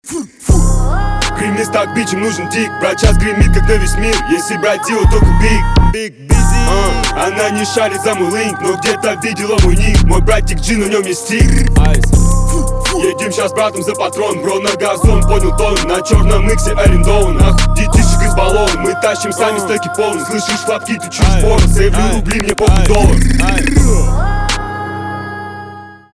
русский рэп
басы , качающие